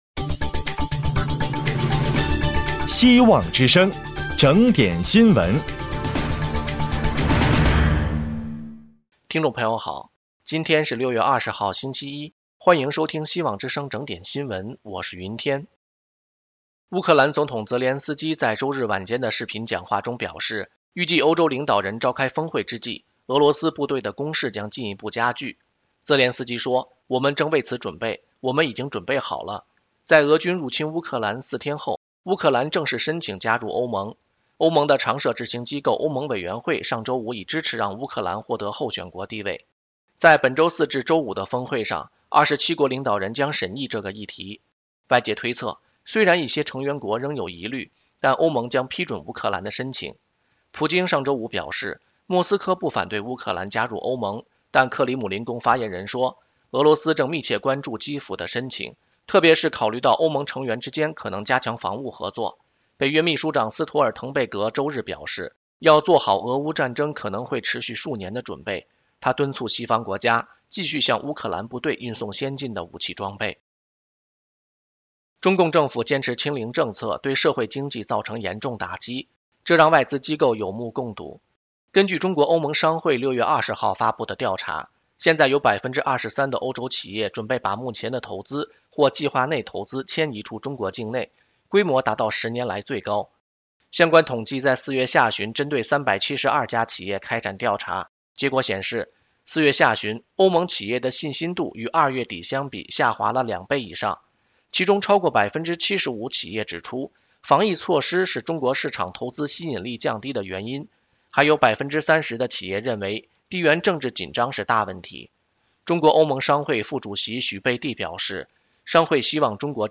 【希望之聲2022年6月21日】（配音：